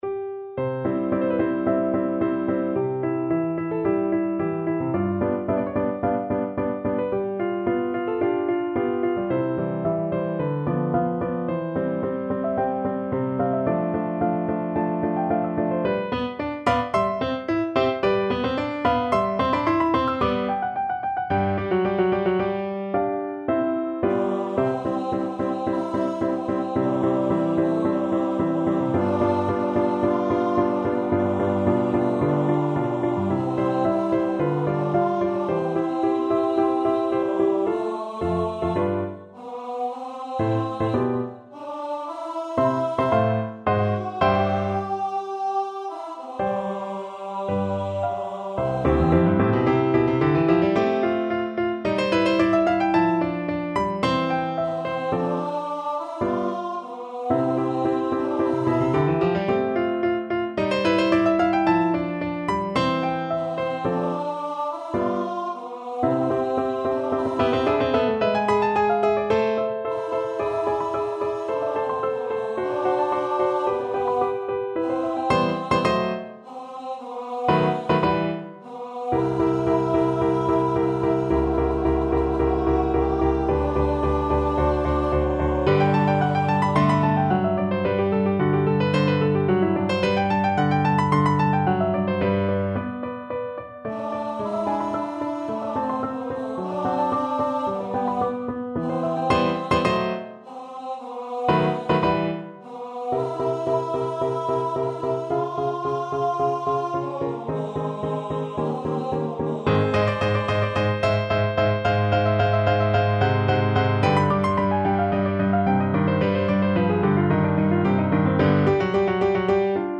Tenor Voice
4/4 (View more 4/4 Music)
Andante =110
C major (Sounding Pitch) (View more C major Music for Tenor Voice )
Classical (View more Classical Tenor Voice Music)